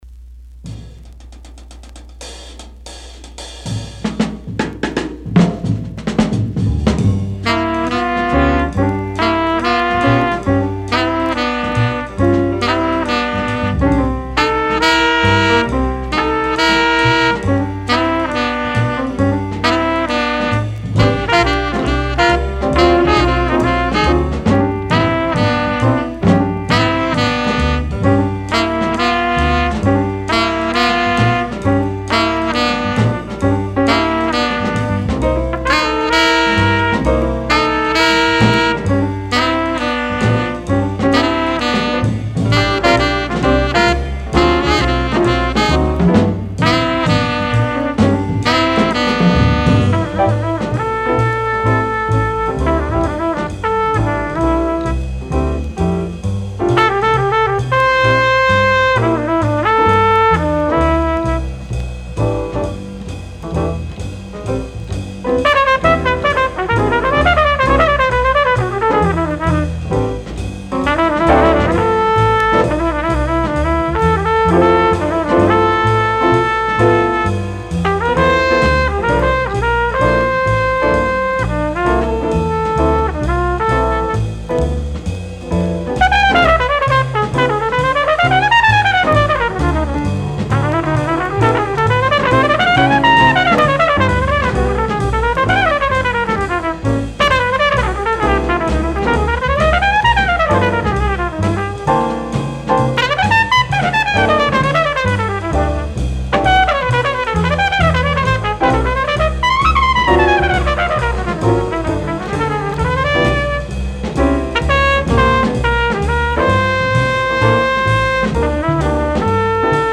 Third mono pressing ca. 1960
recorded February 3, 1960 at Bell Sound Studio B NYC